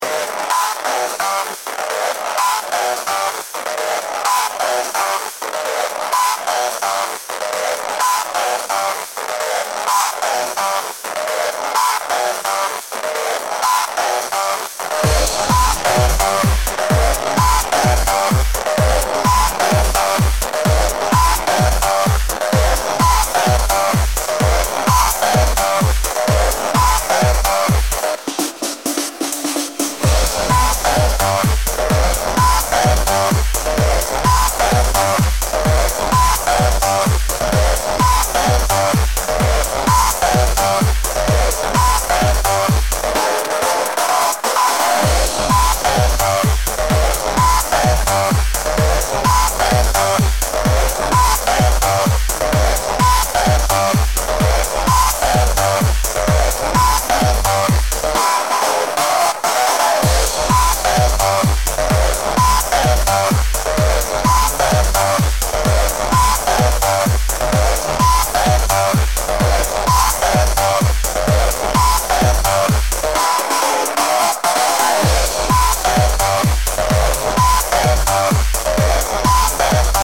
ジャンル: BGM。